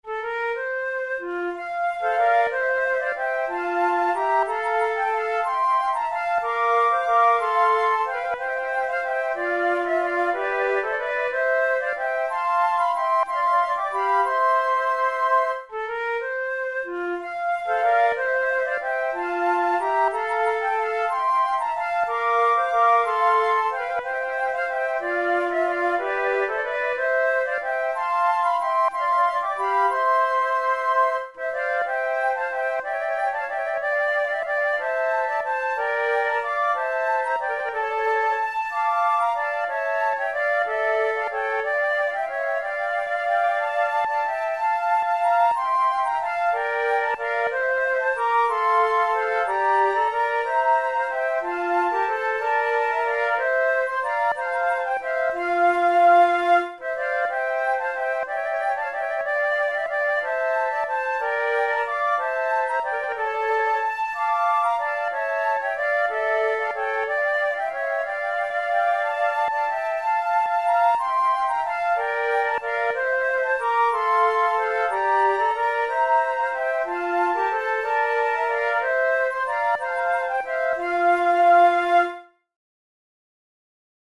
InstrumentationFlute trio
KeyF major
Time signature6/4
Tempo92 BPM
Baroque, Loures, Sonatas, Written for Flute